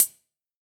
UHH_ElectroHatD_Hit-18.wav